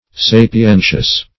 Sapientious \Sa`pi*en"tious\